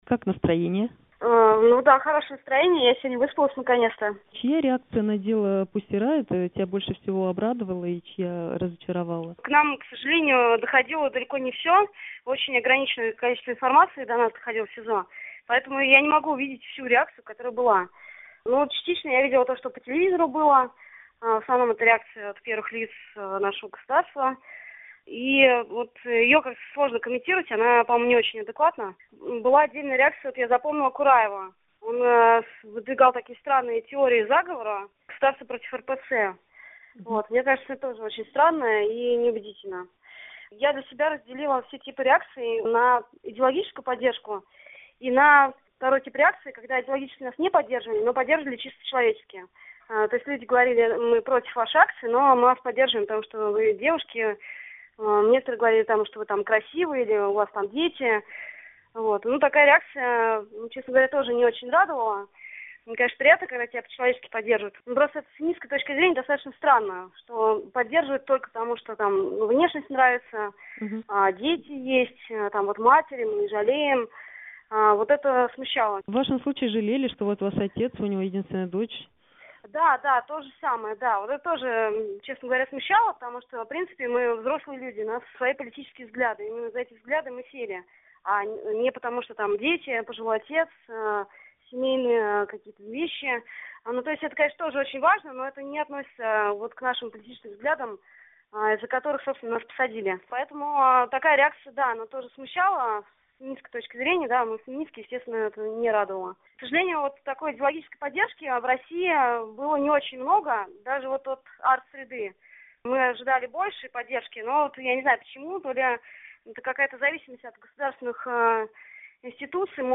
Интервью Екатерины Самуцевич Радио Свобода